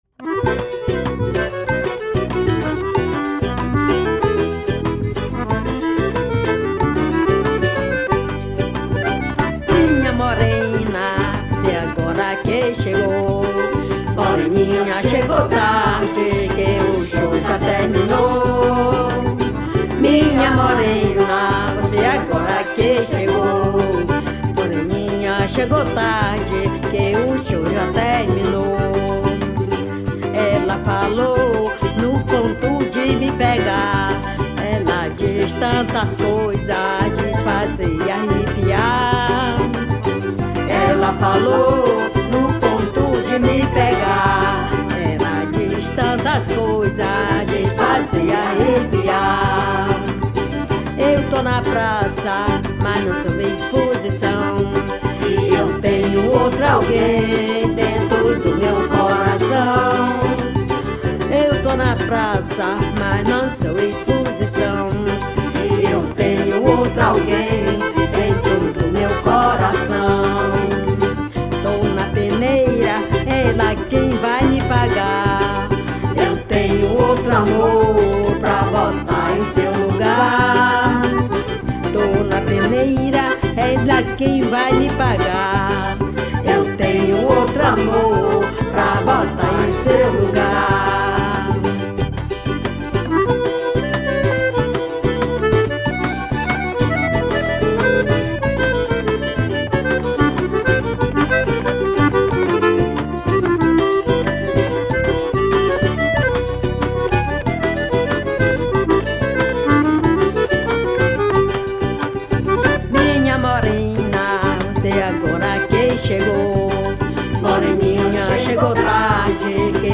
1248   03:29:00   Faixa:     Forró